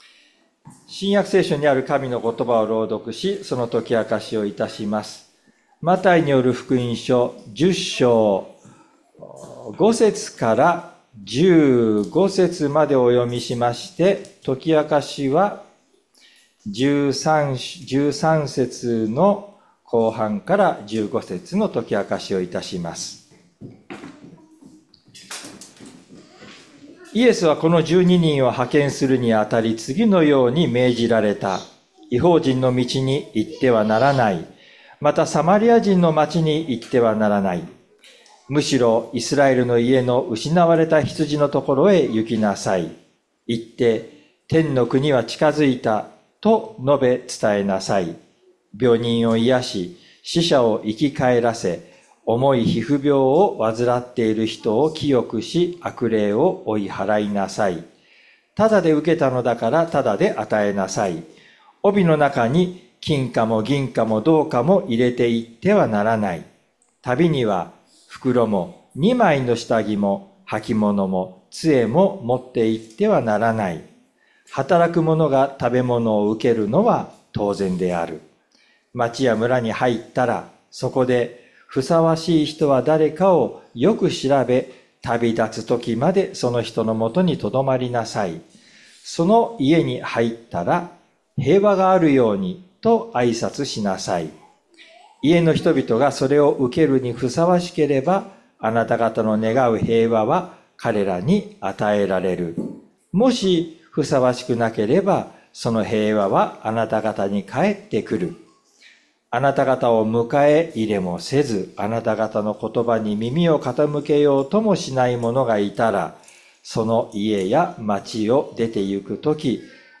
礼拝説教を録音した音声ファイルを公開しています。